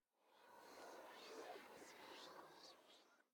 Minecraft Version Minecraft Version latest Latest Release | Latest Snapshot latest / assets / minecraft / sounds / ambient / nether / soulsand_valley / whisper2.ogg Compare With Compare With Latest Release | Latest Snapshot
whisper2.ogg